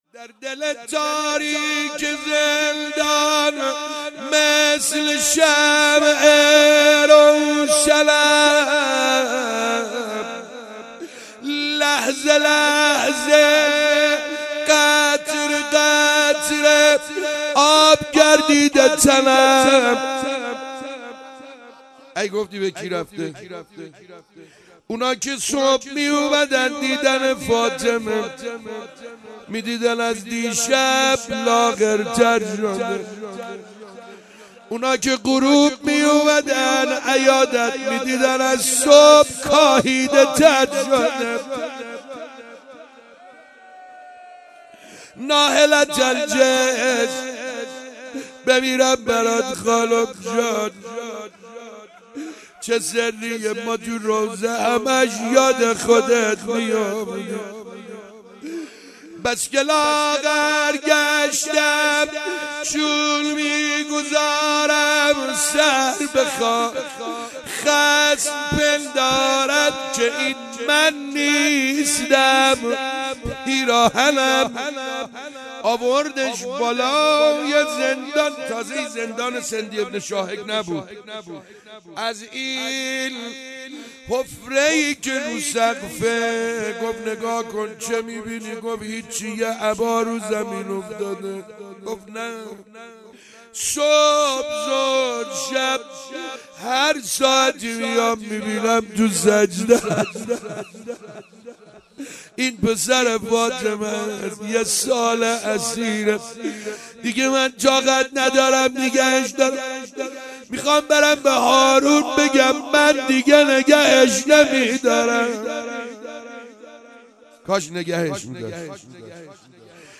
شهادت سعید حدادیان روضه مداحی